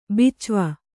♪ bicva